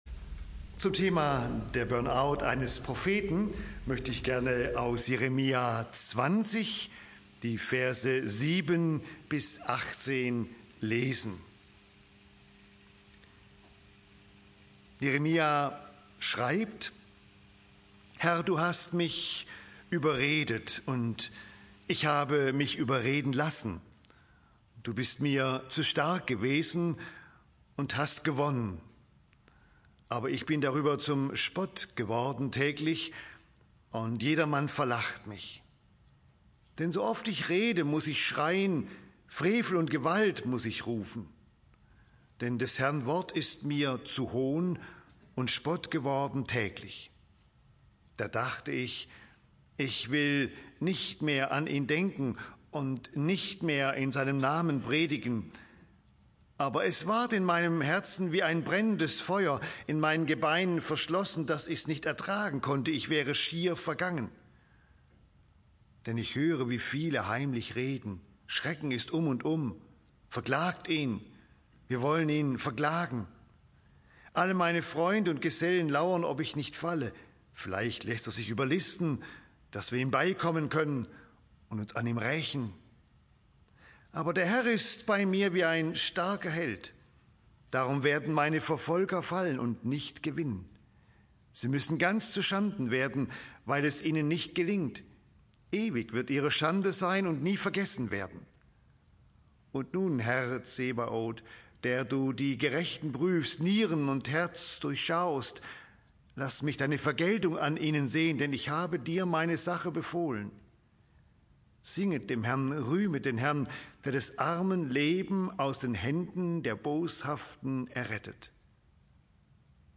Das Burnout eines Propheten (Jer. 20) - Bibelstunde